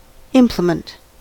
implement: Wikimedia Commons US English Pronunciations
En-us-implement.WAV